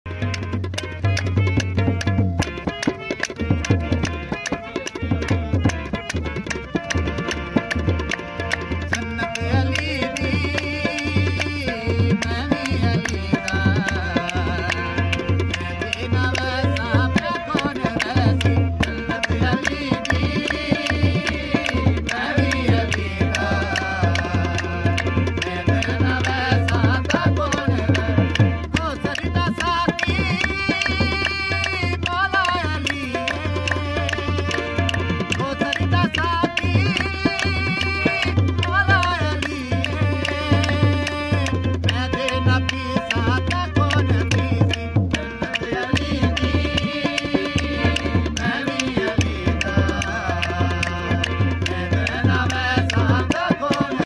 qaseda